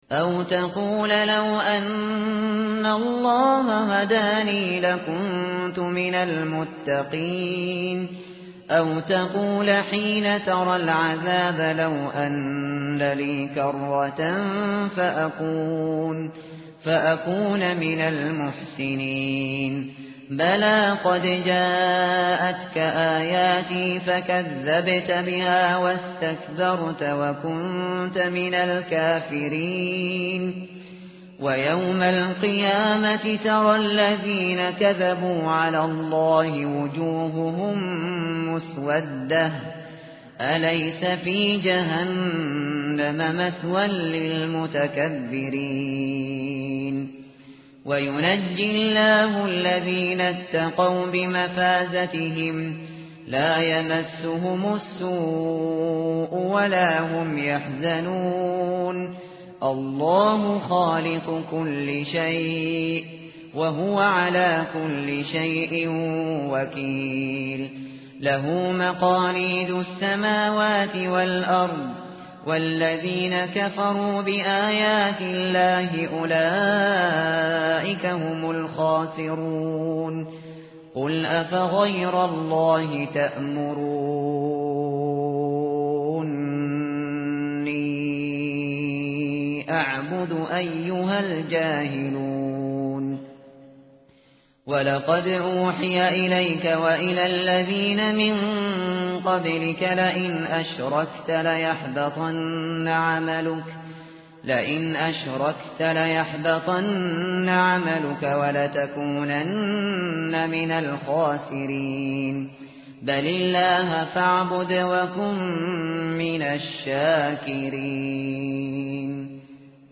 tartil_shateri_page_465.mp3